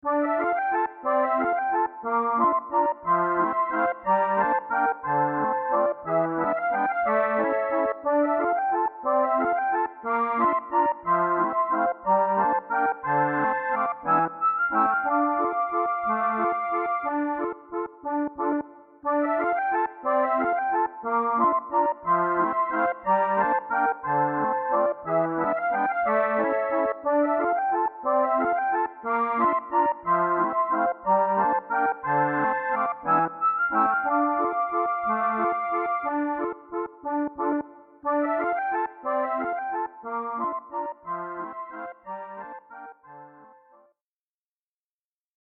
本物のアコーディオン曲というよりいかにもゲームシーンで使われる
大道芸人がいるところに近づくと流れてくるような短い曲です